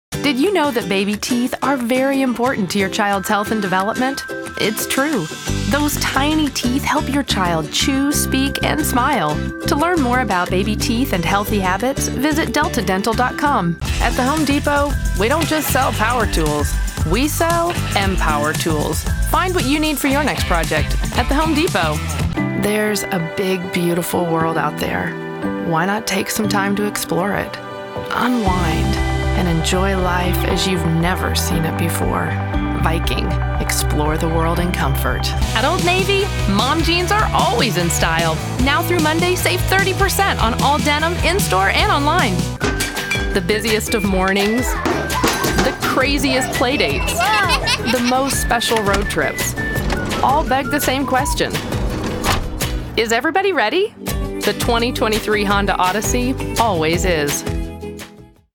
Commercial Demo
General American, East Texas, Pacific Northwest, California, Southern, Character Brooklyn